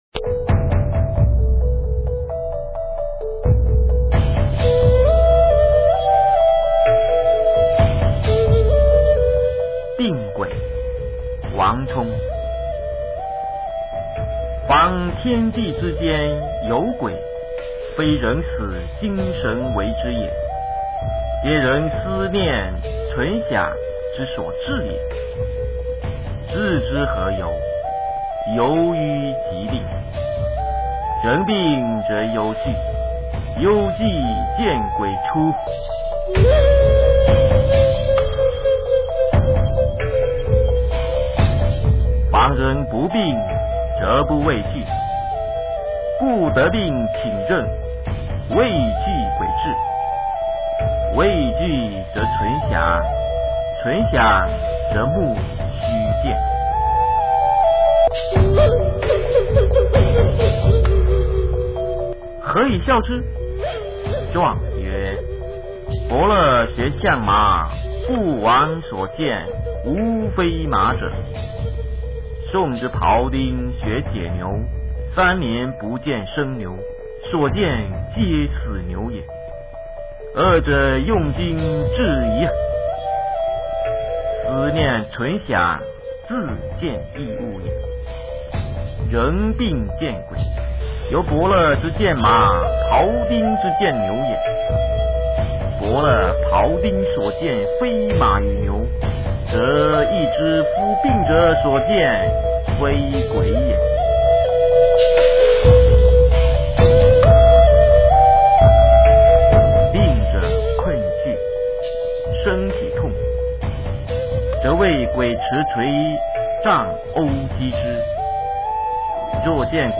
王充《订鬼》原文和译文（含朗读）　/ 王充